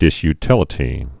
(dĭsy-tĭlĭ-tē)